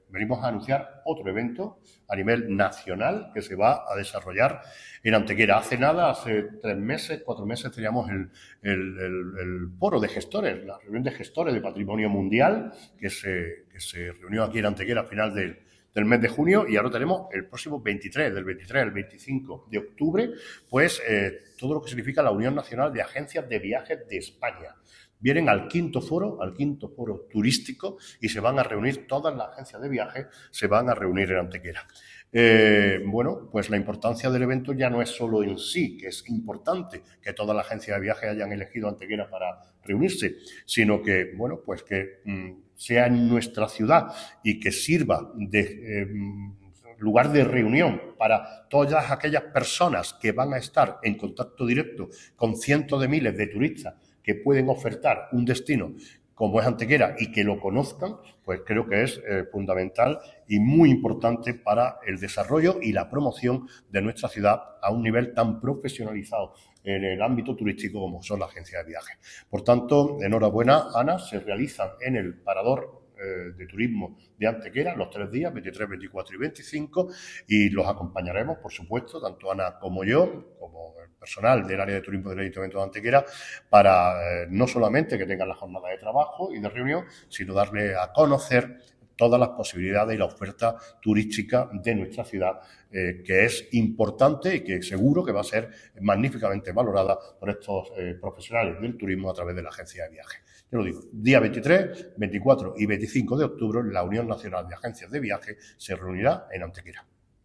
El alcalde de Antequera, Manolo Barón, y la teniente de alcalde delegada de Turismo, Ana Cebrián, confirman que nuestra ciudad ha sido la elegida por la Unión Nacional de Agencias de Viaje de España (UNAV) para la celebración de la quinta edición de su Foro Turístico.
Cortes de voz